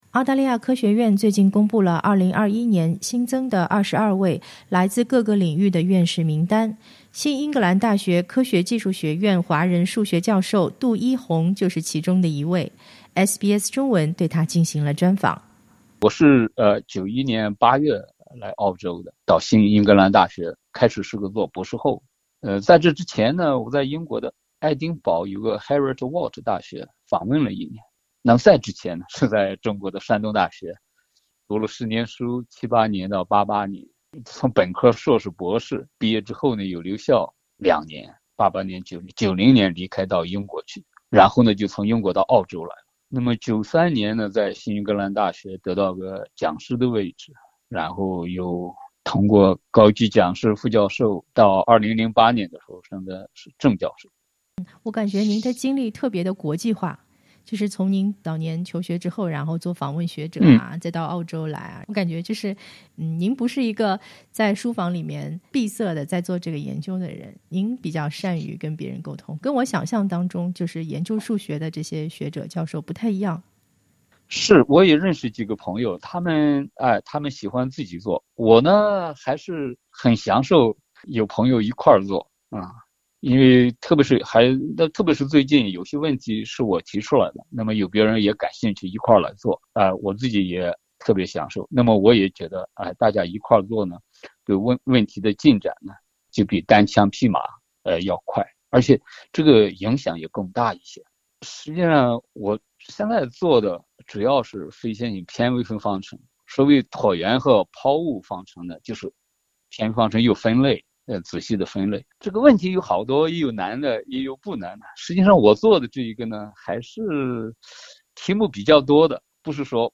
在接受SBS中文专访时，他为公众解释了新冠疫情数学模型的难点在哪里。